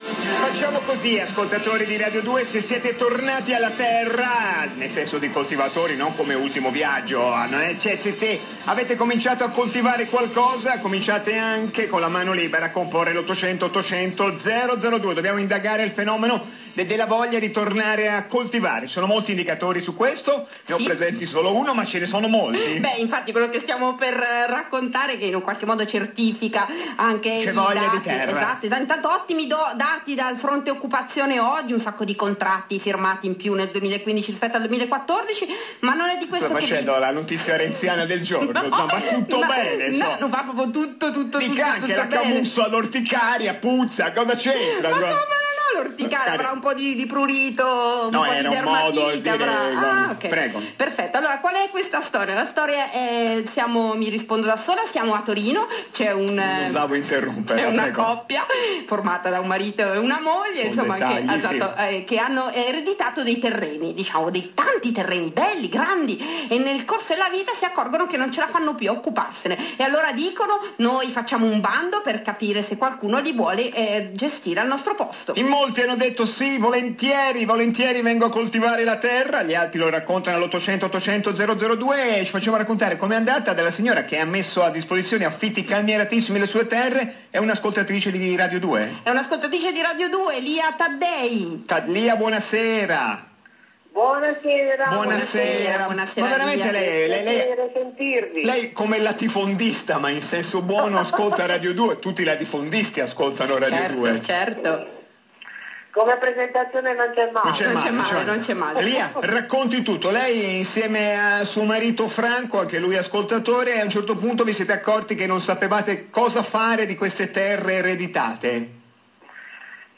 Intervista Caterpillar (radio2)
Intervista-Radio-2_antiche-terre.m4a